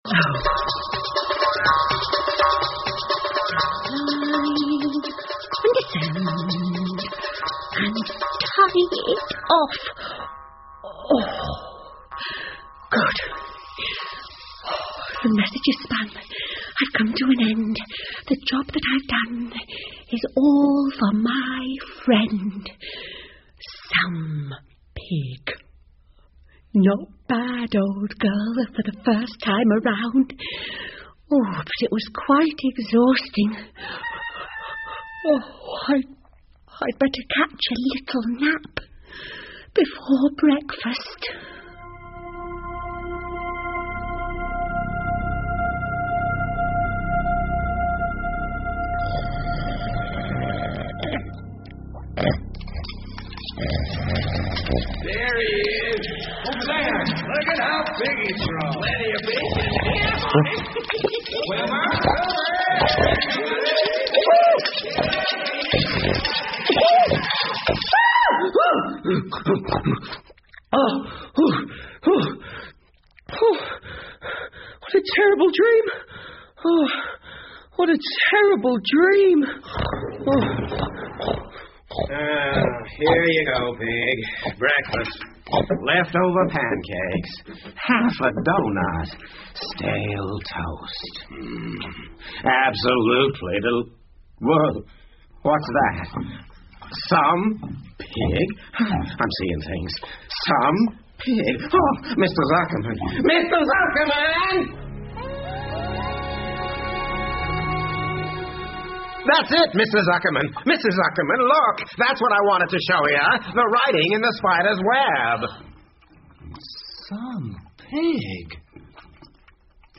夏洛的网 Charlottes Web 儿童广播剧 6 听力文件下载—在线英语听力室